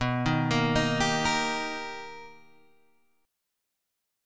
B Augmented Major 7th